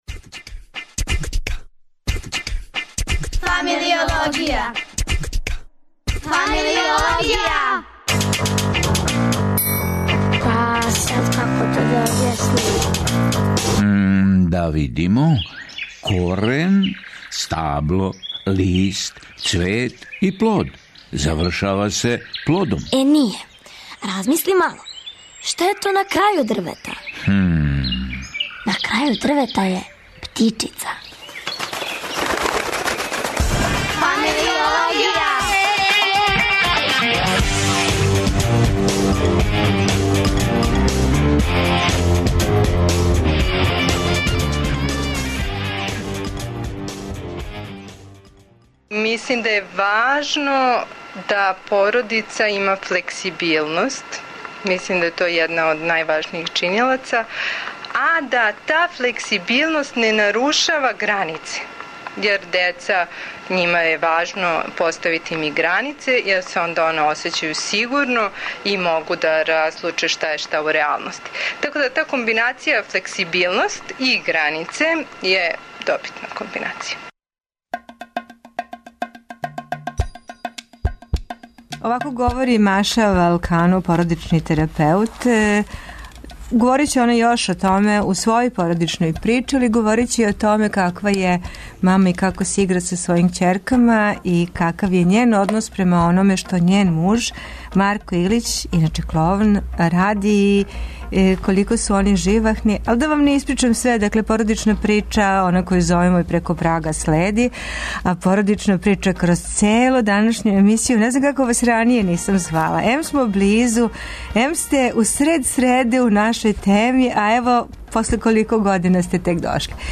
Гост у студију је